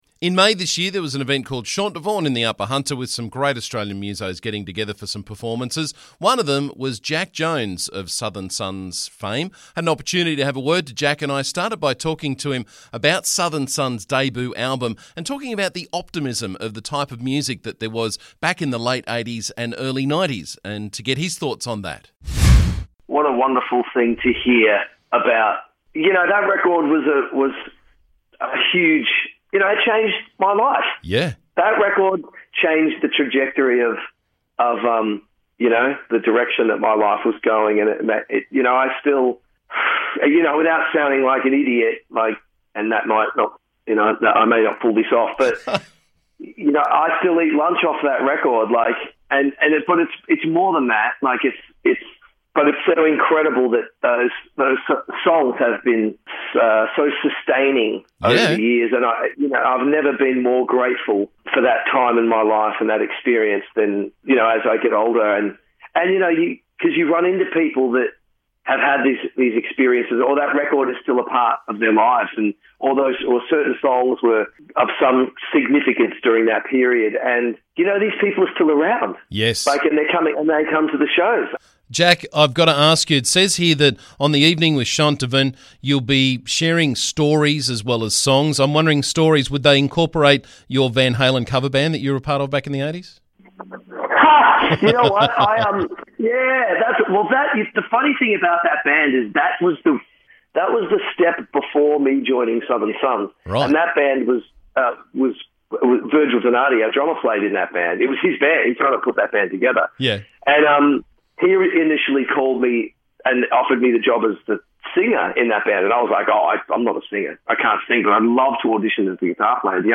Earlier this year Jack Jones was here in the Upper Hunter for Chant Du Vin and we had an opportunity to have a chat about the gig and his career.